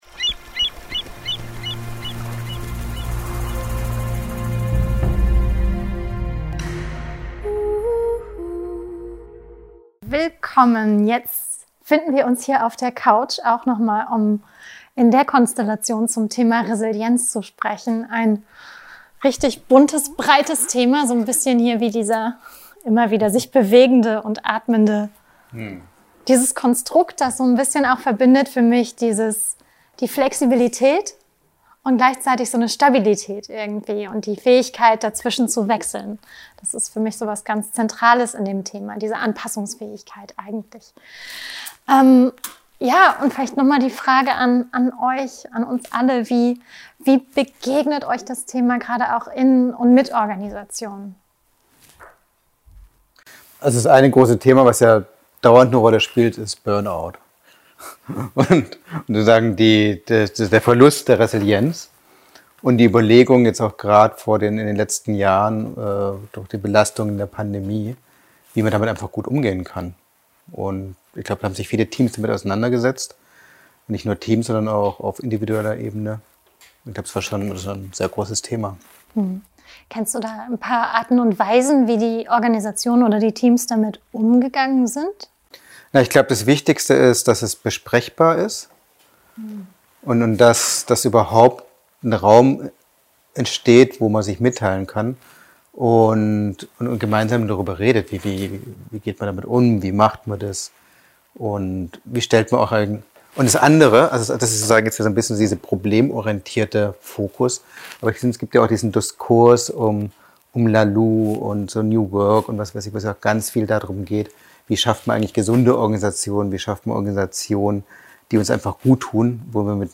Entdecke in unserem Kamingespräch, wie Teams und Organisationen Verantwortung für Stress, Resilienz und Gesundheit übernehmen können.